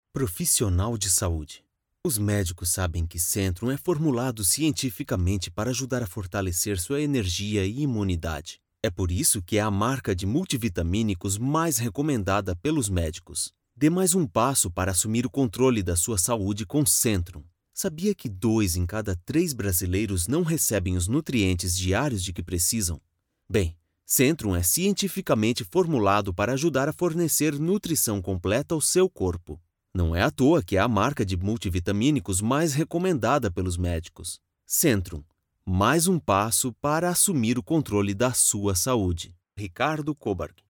Narración
Neumann TLM193 Microphone
Acoustic and soundproof Booth
BarítonoBajo